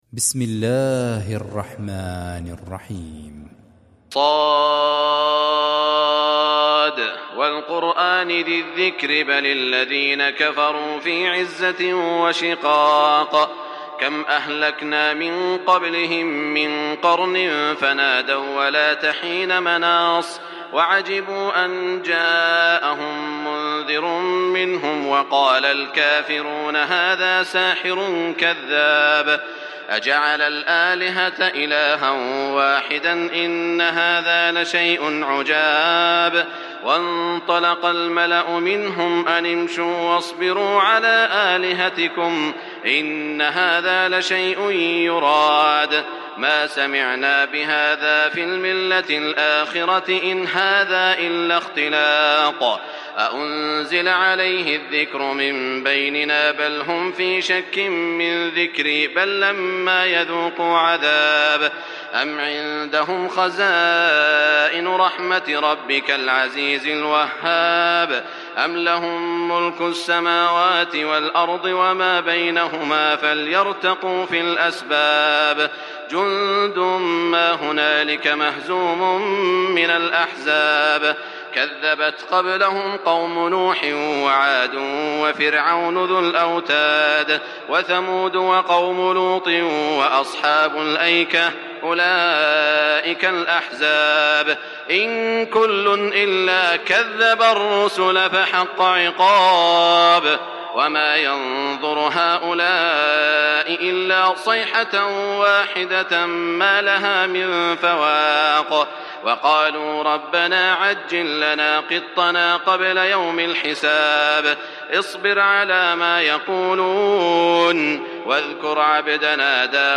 استمع الى باقي السور للقارئ خليفة الطنيجي – المرتل